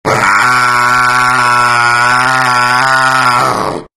Звук пантеры когда у нее отбирают мясо